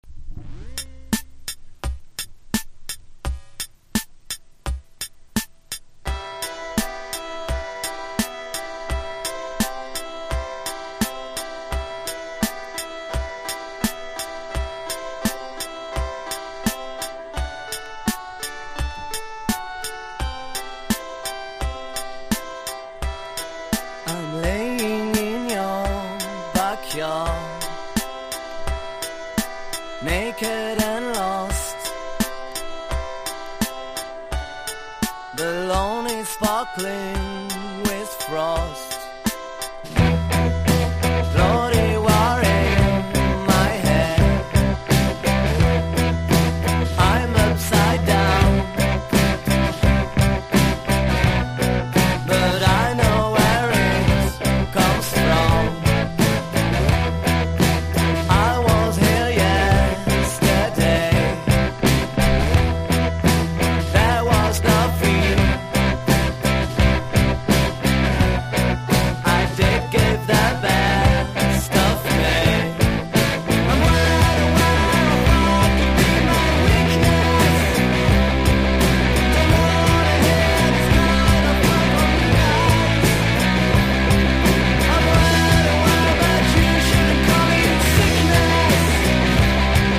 フランスの人気ガレージ･バンド
商品情報 '60年代のガレージ・ロックから影響を受けたサウンドで、幅広い年代に推薦できる1枚です！